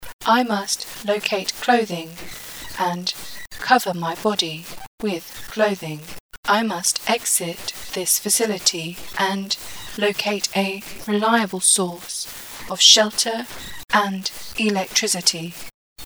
Robot Voice Audio Tutorial
Distortion or noise can also be added.
Will add a noisy kind of static to the audio, like this:
MELI-example-Mix-Pitch-Background-Noise.mp3